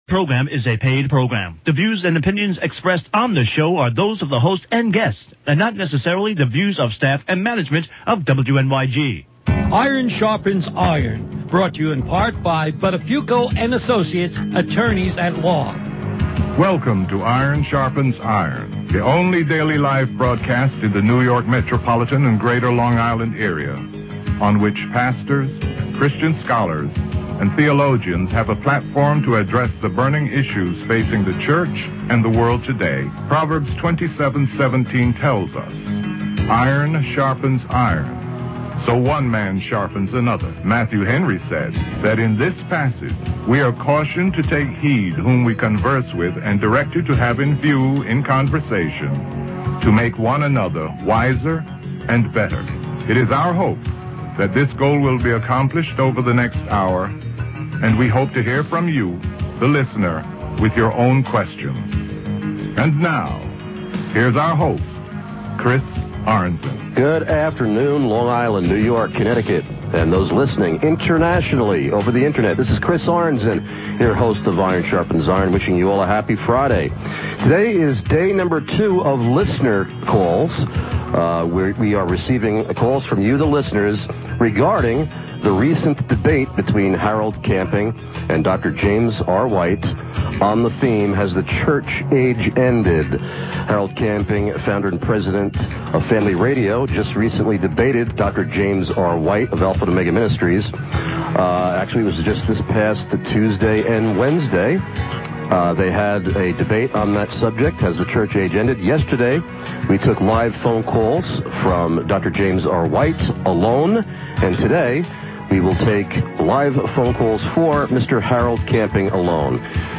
This is the last of the four programs; Harold Camping’s analysis of the debate.